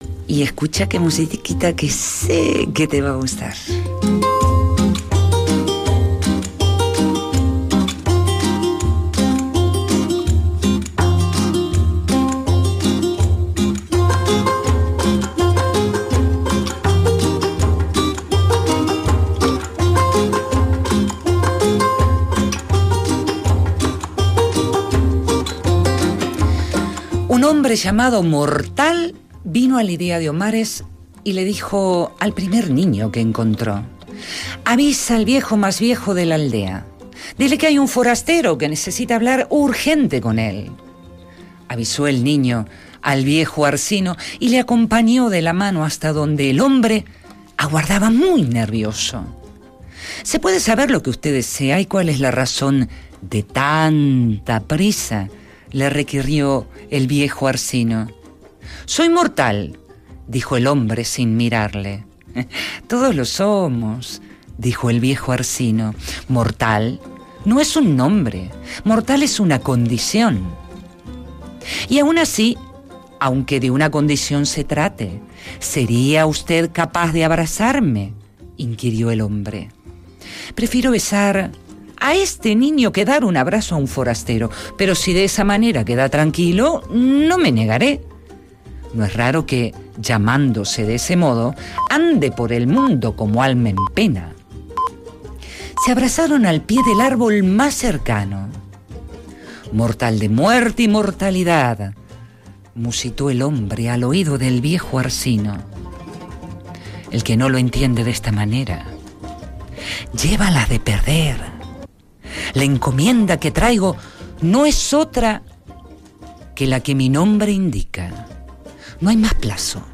Rapsodeando ''Un mortal'' de Luis Mateo Diez